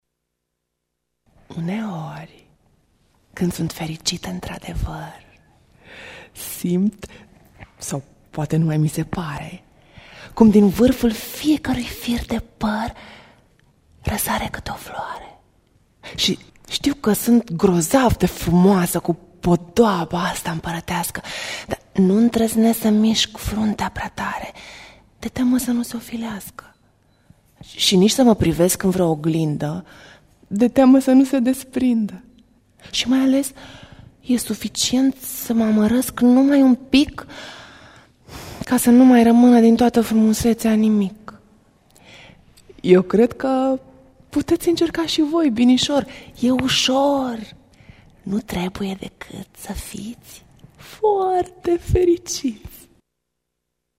invitate la ediţia a II-a a Festivalului “W.A.Mozart “de la Braşov în cadrul căruia au susţinut recitalul “Pianul cu poeme”.